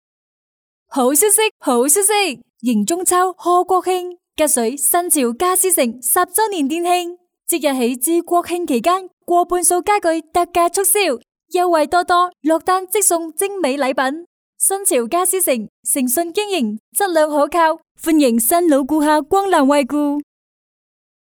女粤20粤语广告配音-新声库配音网
4 女粤20_广告_促销_国庆家私城粤语 女粤20
女粤20_广告_促销_国庆家私城粤语.mp3